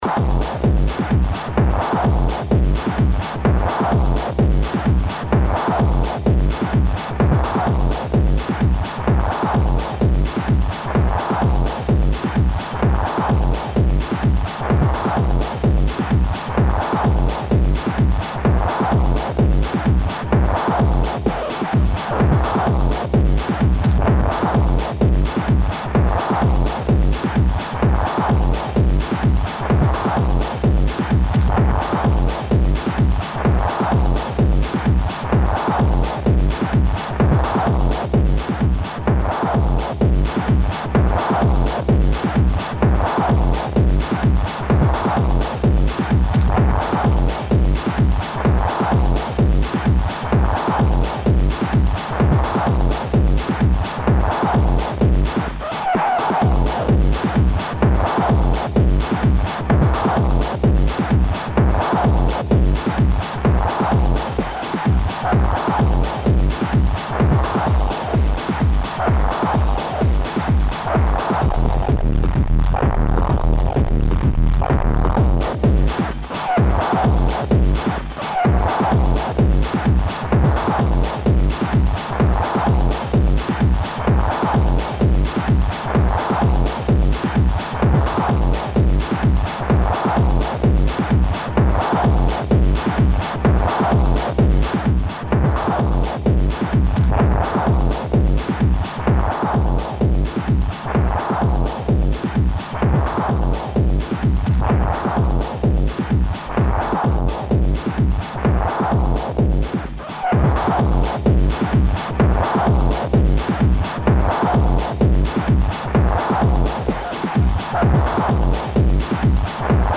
more distorted shit